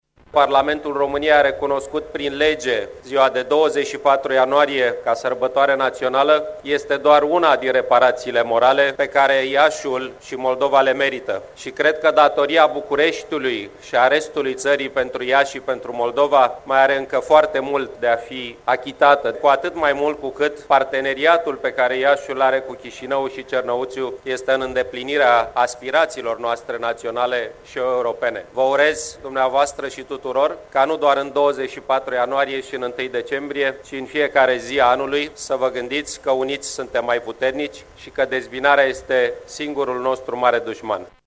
Un mesaj de unitate a transmis şi premierul Vitor Ponta, de la tribuna oficială, a ceremonilor de la Iaşi, dedicate Unirii Principatelor: